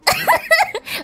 Oni Laugh Sound Effect Free Download
Oni Laugh